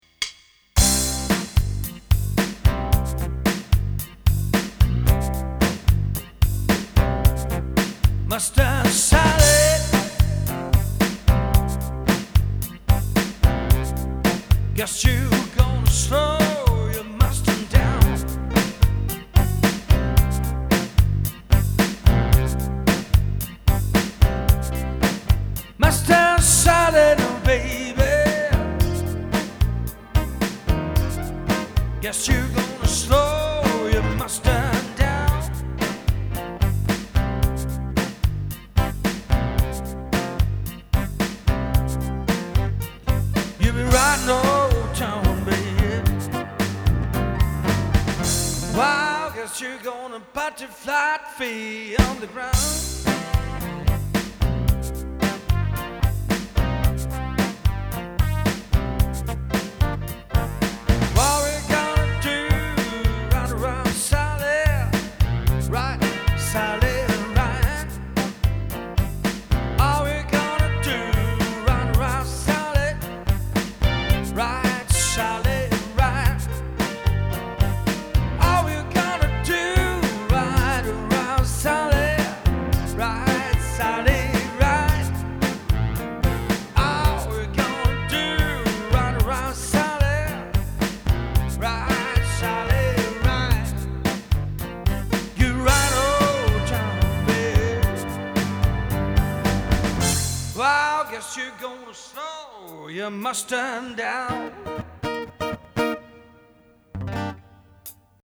• Coverband
• Solomusiker